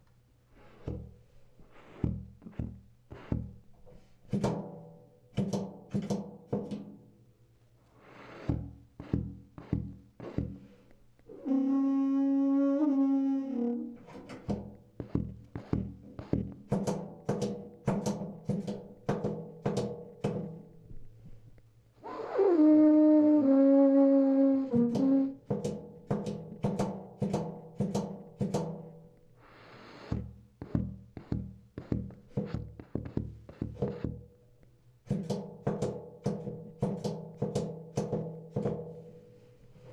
TUBASAMPLES: